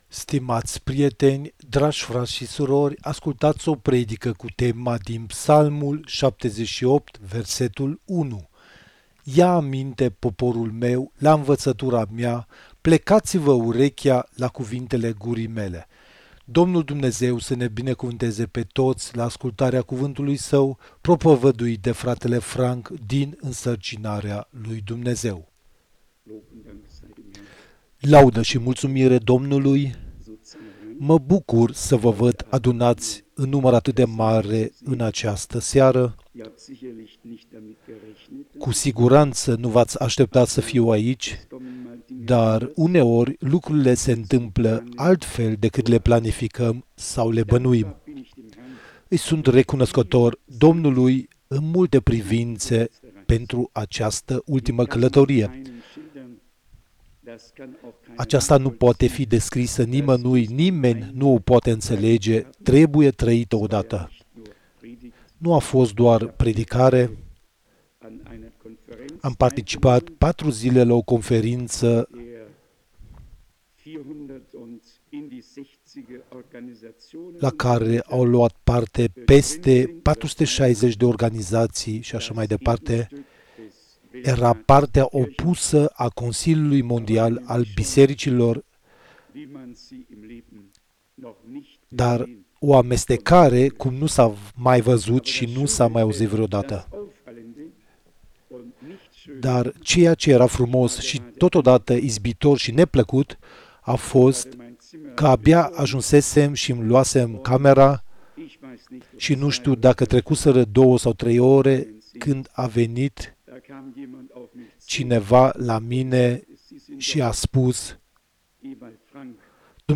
Predica - miercuri 27 august 2025